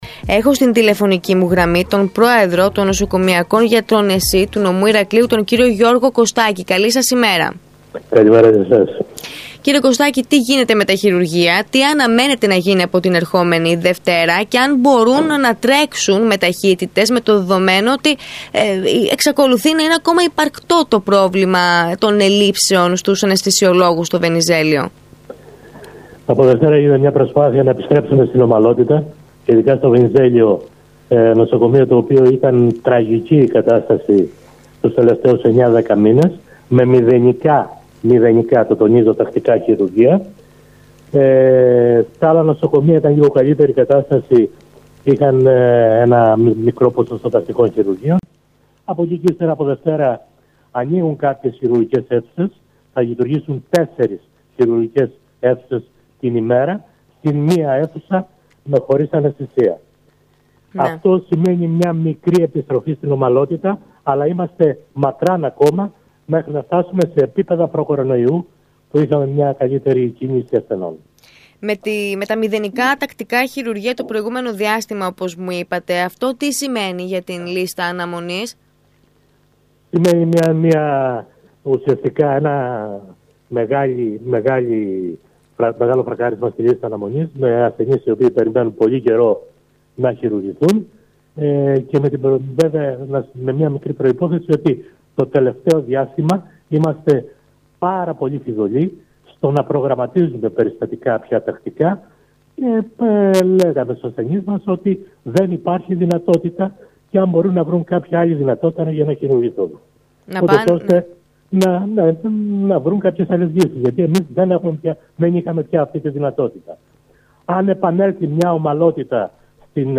μίλησε στον ΣΚΑΪ Κρήτης 92,1